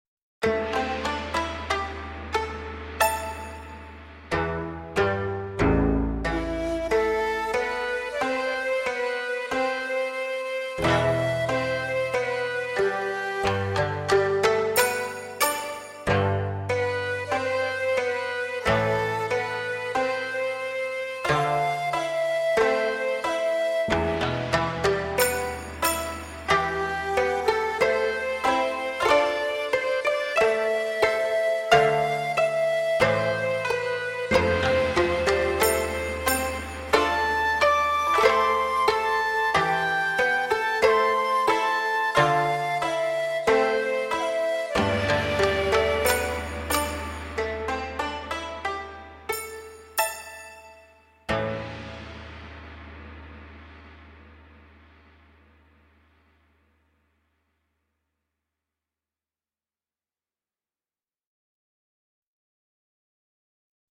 VS Lady Meng Jiang (backing track)